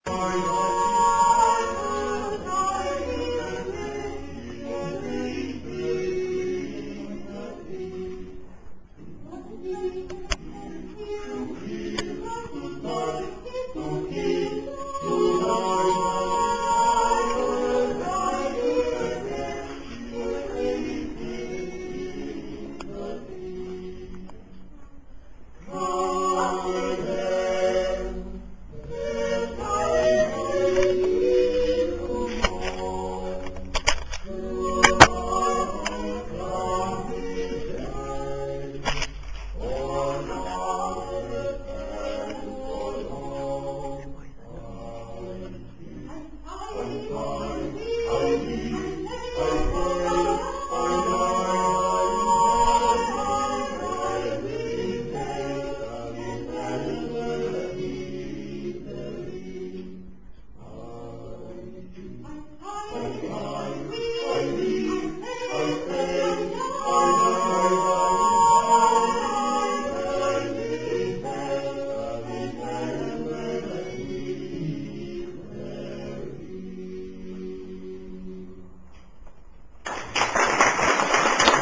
18. komorní koncert na radnici v Modřicích
Vokální kvintet a Smíšený pěvecký sbor města Modřice - 14. prosince 2005
Ukázkové amatérské nahrávky ve formátu WMA: